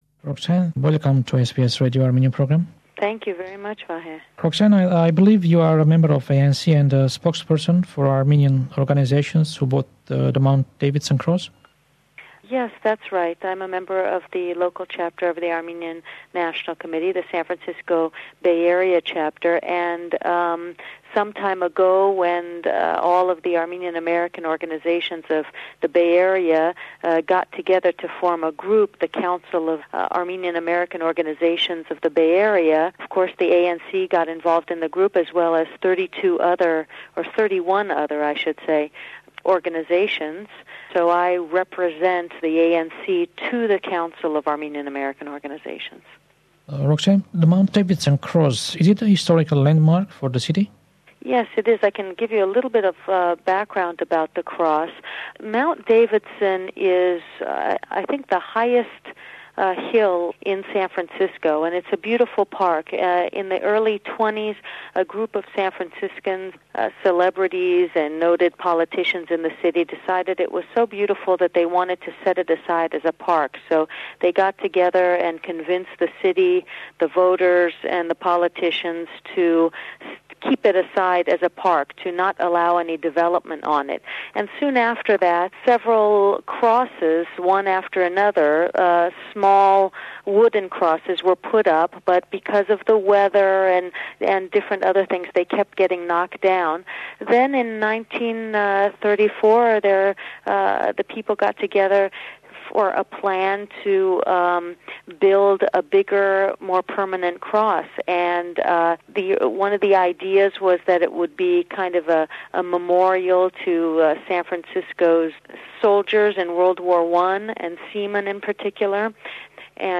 The interview was broadcast January 28, 1998. The topic of the interview is San Franciscos Armenian community and its struggle to buy and preserve the Mt. Davidson Cross and transform it to a monument to the victims of the Armenian Genocide.